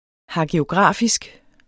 Udtale [ hagioˈgʁɑˀfisg ] eller [ hɑgioˈgʁɑˀfisg ]